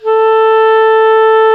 WND  CLAR 0C.wav